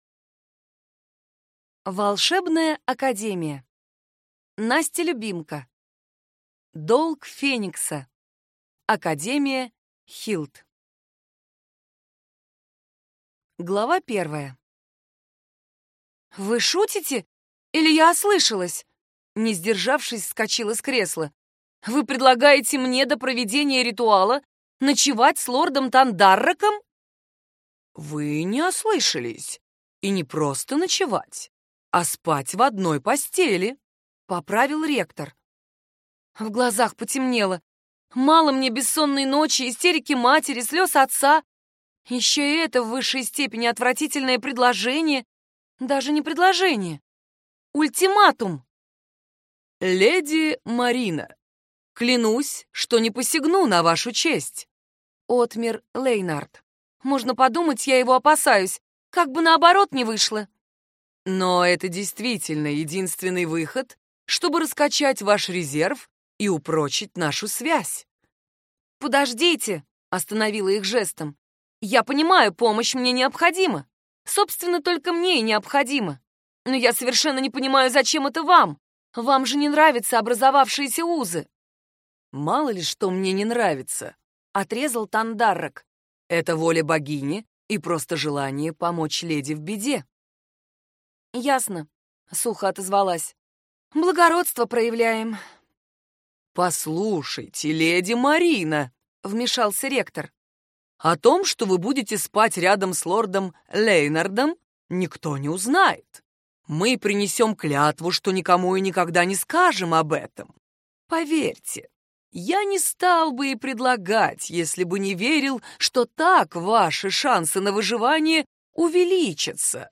Аудиокнига Академия ХИЛТ. Долг феникса | Библиотека аудиокниг